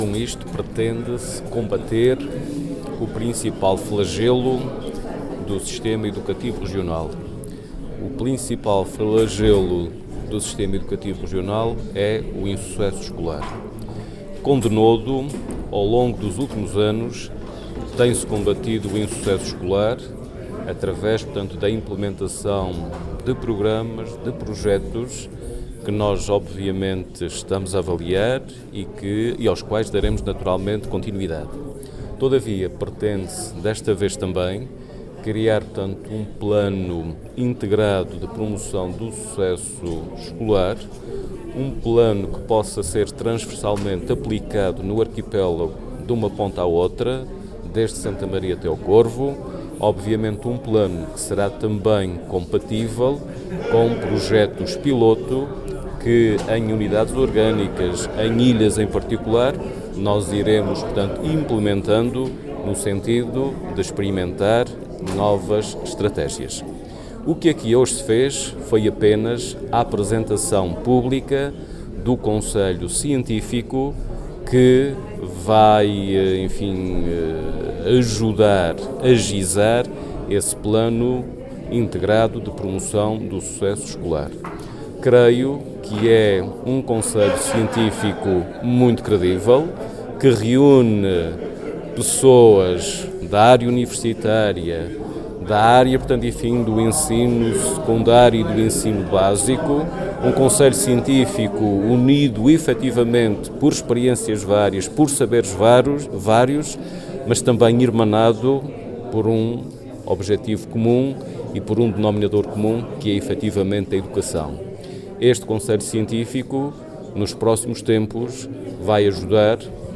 “Com denodo, ao longo dos últimos anos, tem-se combatido o insucesso escolar através da implementação de programas, de projetos, que estamos a avaliar e aos quais daremos naturalmente continuidade”, salientou Avelino Meneses, em declarações aos jornalistas à margem da cerimónia de apresentação do Conselho Científico do ProSucesso.